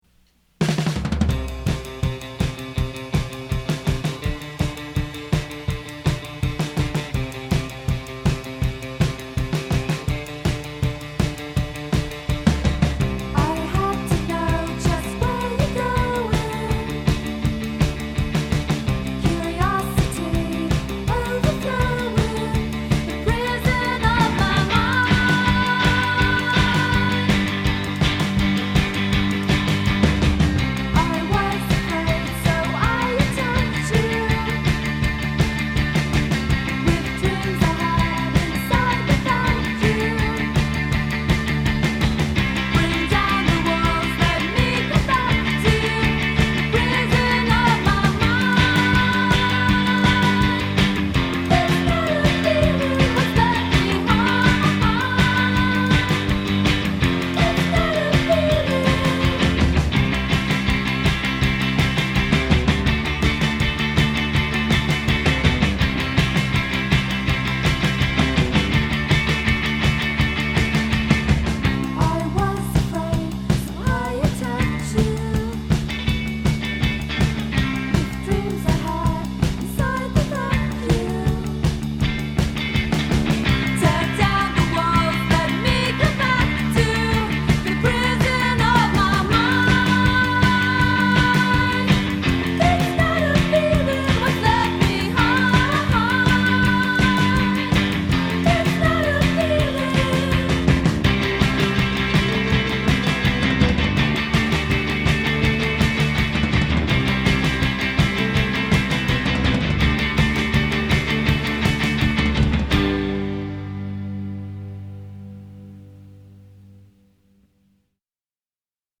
duo
due minuti secchi e tirati
chitarra e batteria a pestare bene